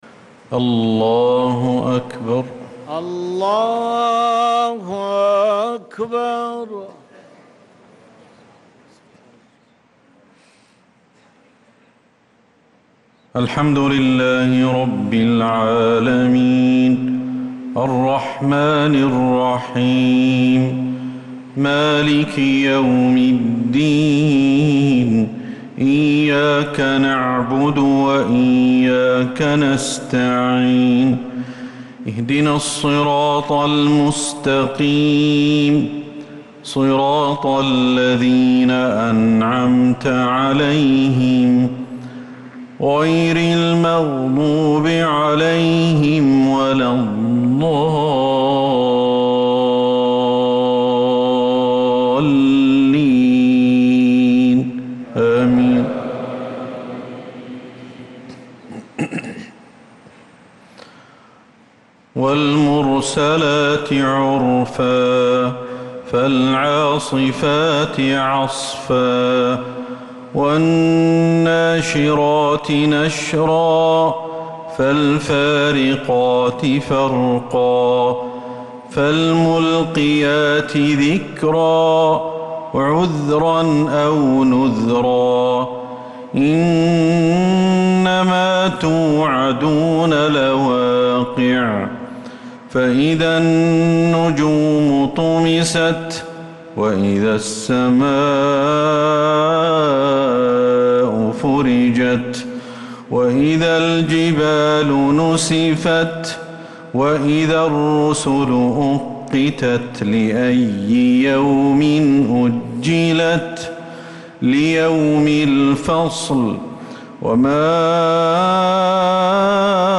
صلاة العشاء للقارئ أحمد الحذيفي 8 صفر 1446 هـ